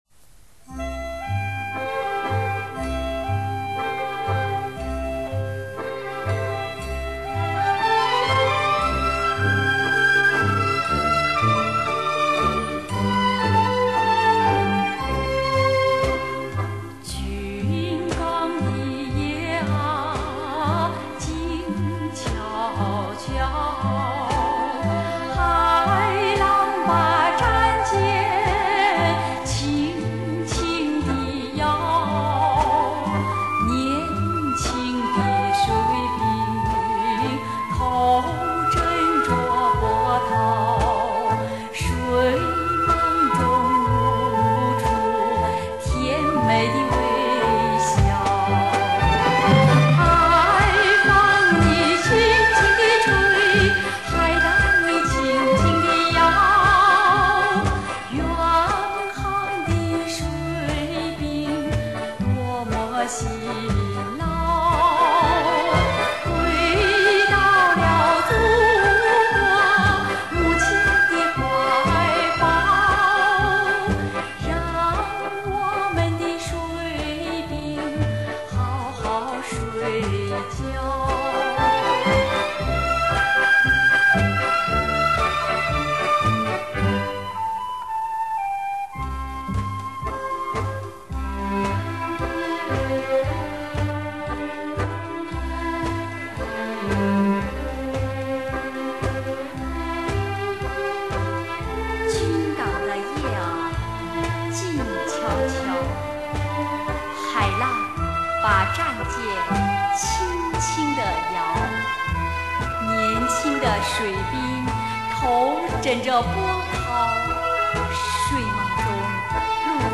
很喜欢原版录音，乐队伴奏韵味独特。